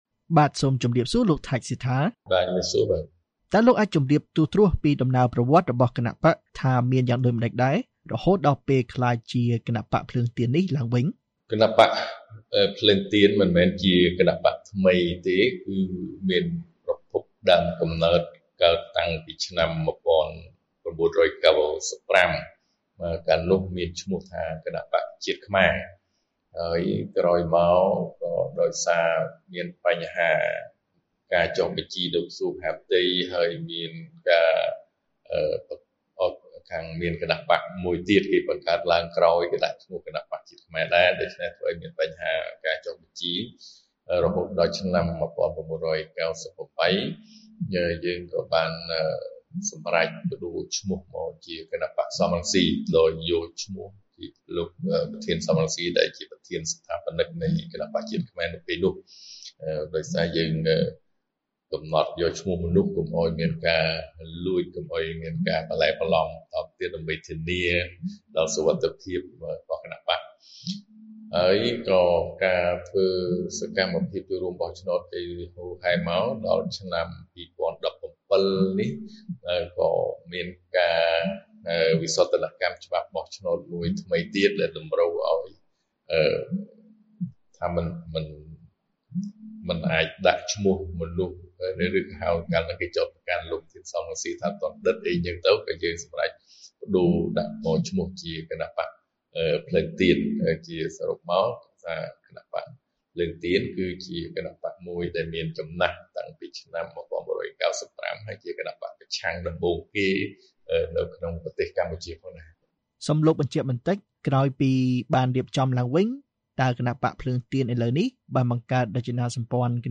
បទសម្ភាសន៍ VOA៖ ប្រធានស្តីទីគណបក្សភ្លើងទៀន បារម្ភអំពីការបន្តគាបសង្កត់ ប៉ុន្តែថា មិនមានជម្រើស